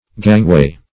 Gangway \Gang"way`\, n. [See Gang, v. i.]